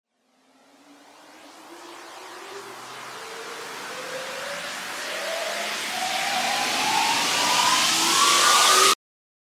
FX-1483-RISER
FX-1483-RISER.mp3